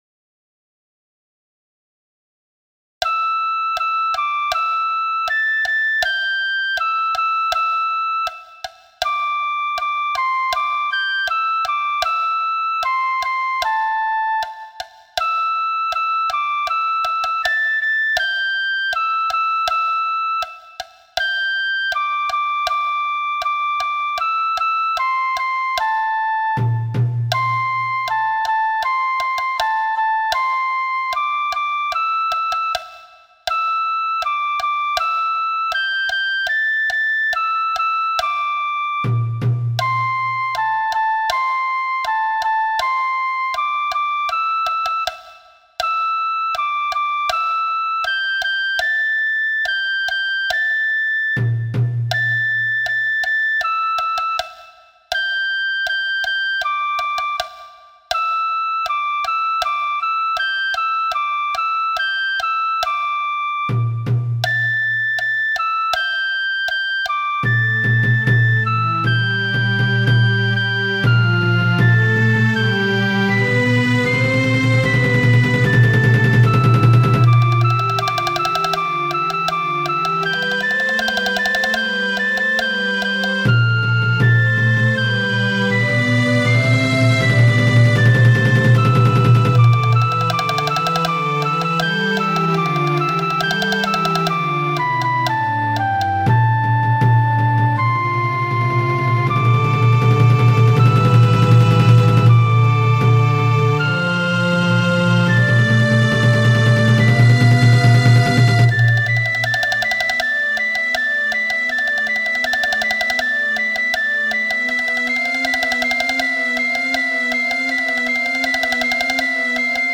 この曲は笛が主役で、
太鼓が一台、
それに、自由に合わせてくれてます。
《　おはやし　》